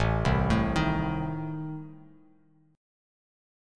Adim chord